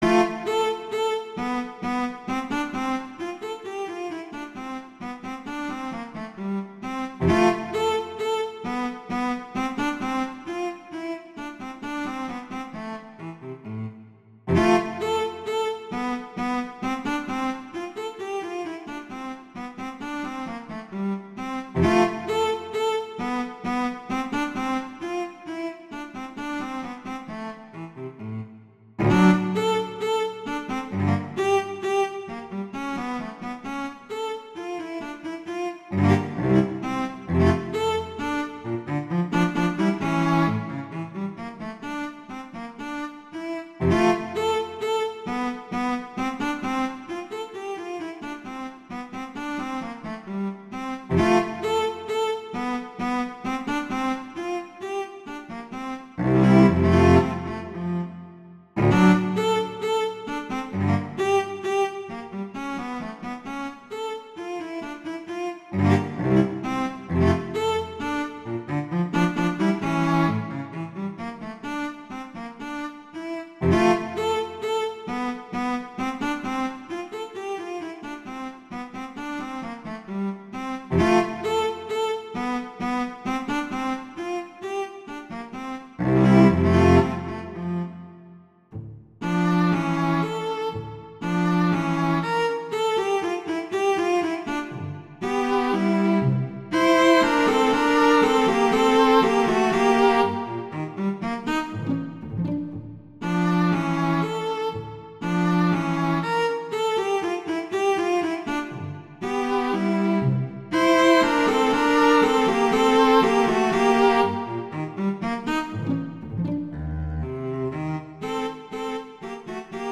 cello solo
classical
D minor, Bb major